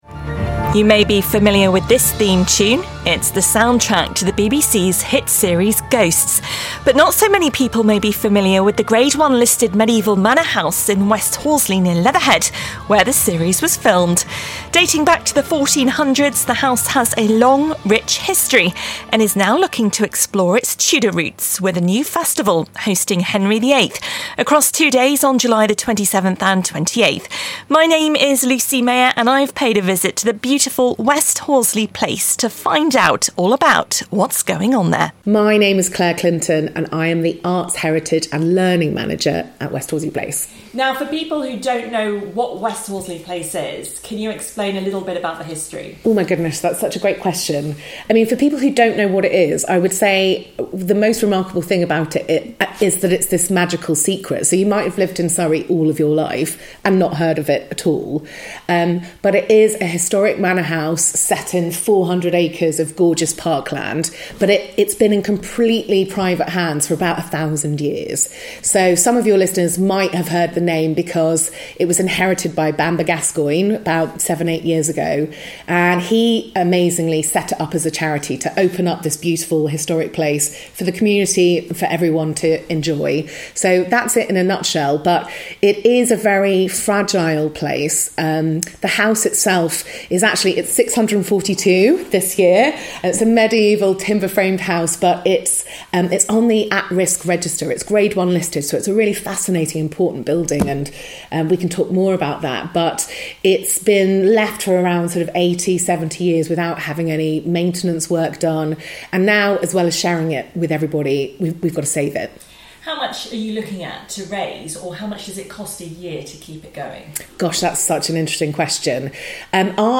reports from West Horsley Place.